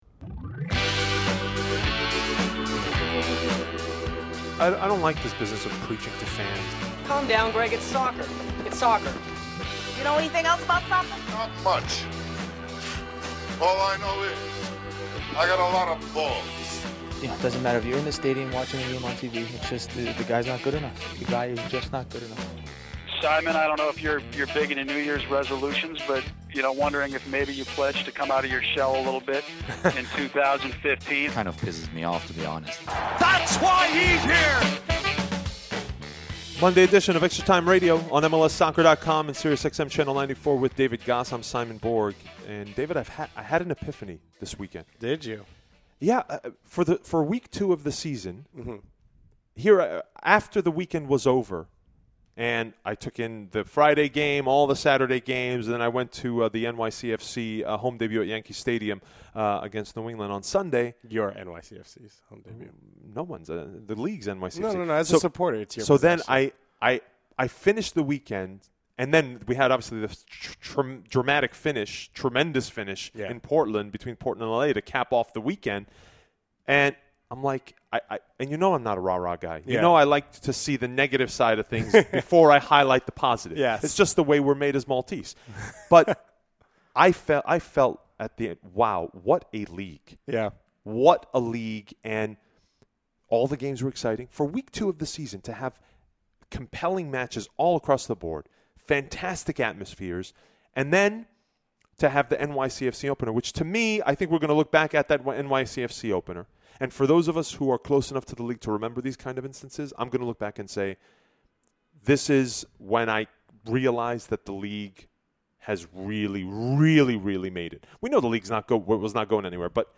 The guys discuss their experiences at the NYCFC opener and the team's ability to capture the imagination of NYC in their first week in town.